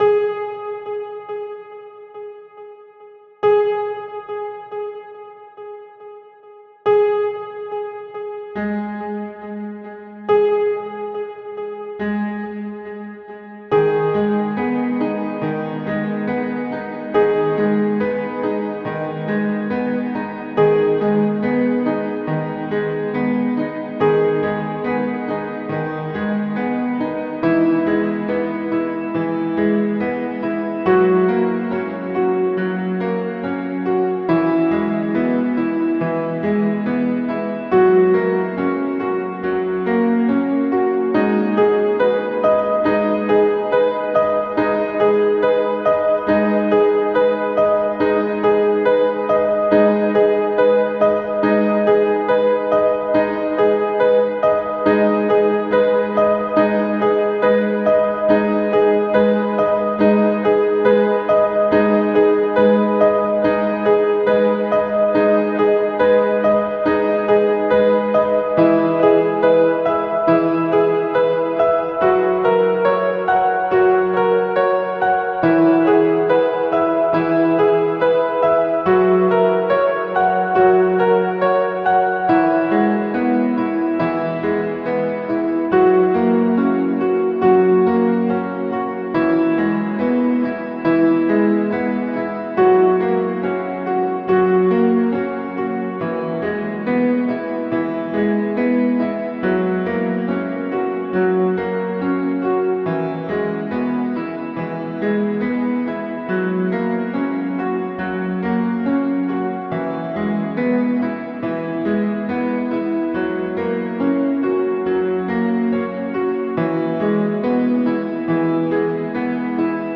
おしゃれな作業向け、静かで壮大なピアノ曲です！
BPM：70 キー：G#m ジャンル：おしゃれ、ゆったり 楽器：ピアノ